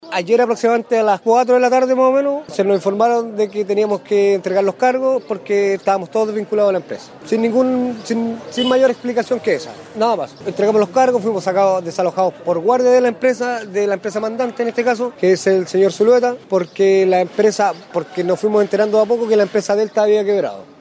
Uno de los dirigentes de los desvinculados